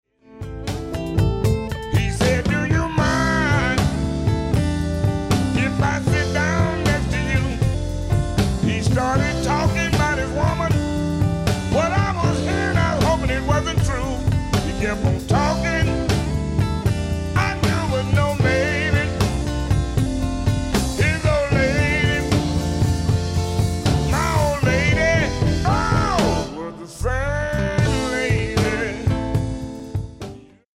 a lot of funk and Motown soul mixed in